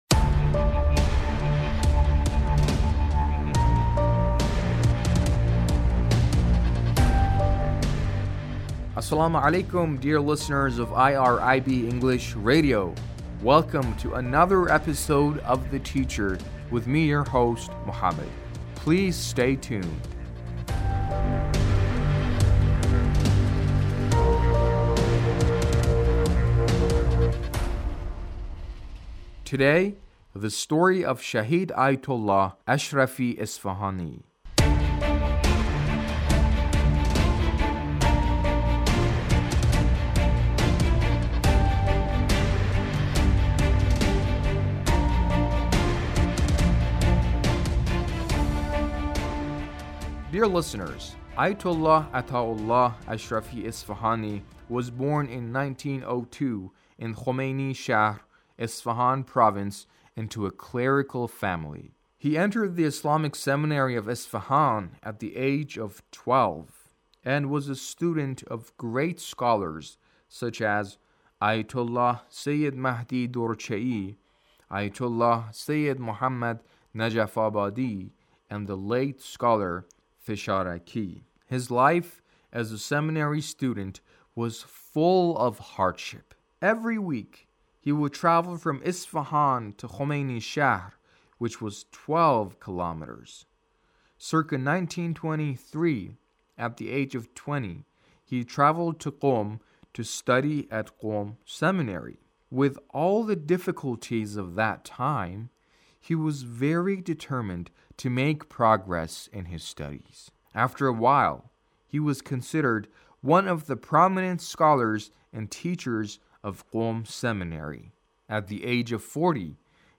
A radio documentary on the life of Shahid Ashrafi Isfahani